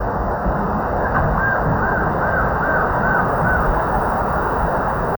Surucuá Amarillo (Trogon chrysochloros)
Nombre en inglés: Atlantic Black-throated Trogon
Provincia / Departamento: Misiones
Condición: Silvestre
Certeza: Fotografiada, Vocalización Grabada
Surucua-amarillo_1.mp3